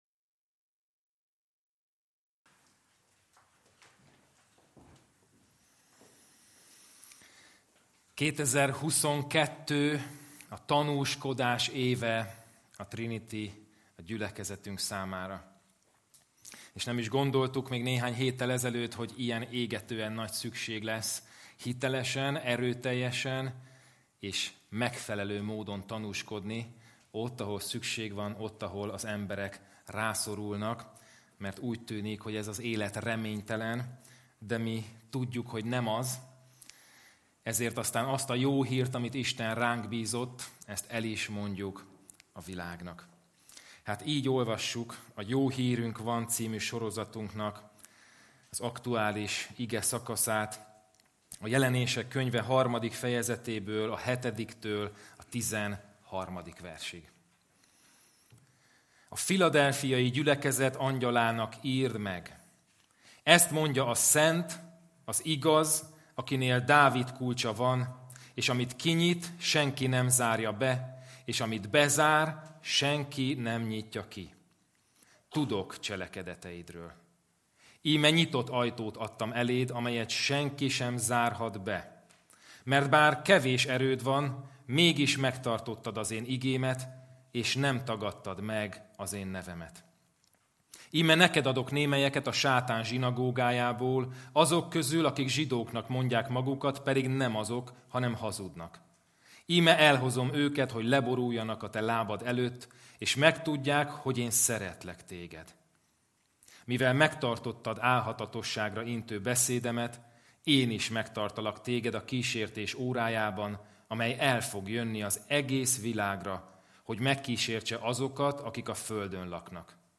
Kategória: Igehirdetés Sorozat: Jó hírünk van!